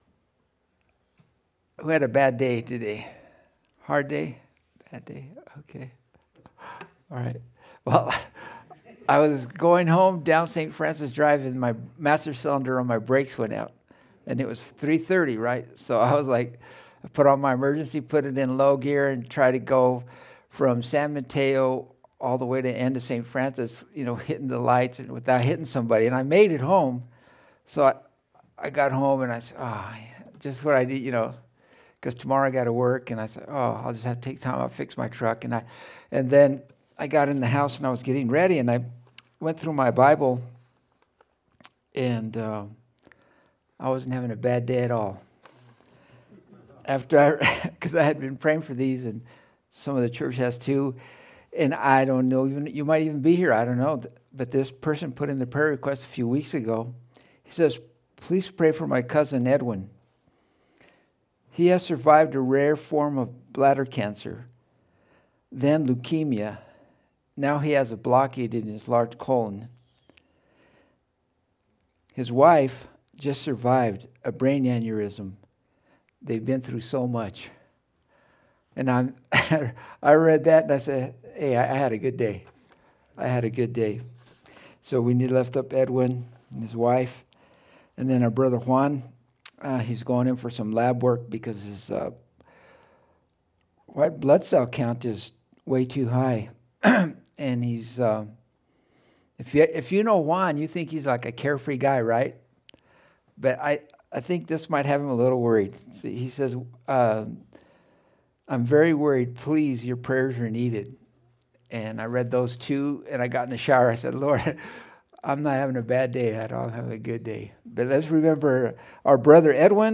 Men of Valor Sermons - Sonlit Hills Christian Fellowship